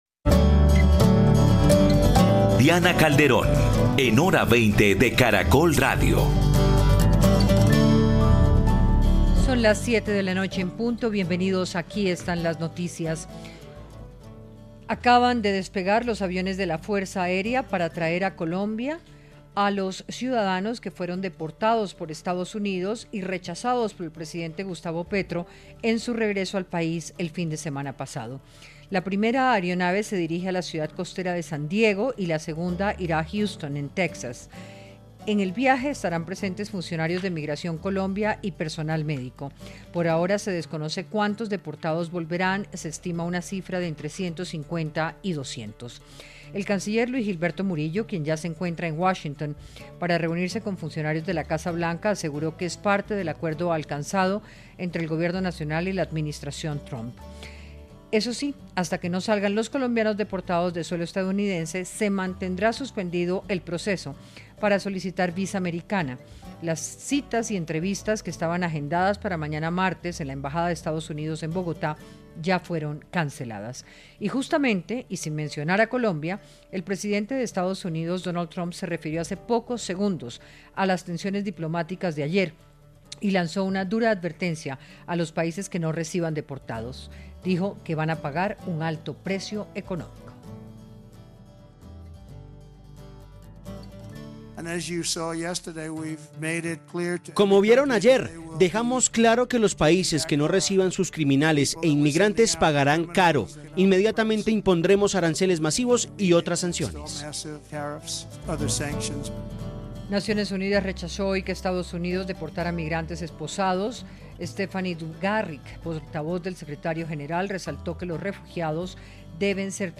Panelistas analizaron el impacto político, diplomático y humanitario de las tensiones entre Donald Trump y Gustavo Petro.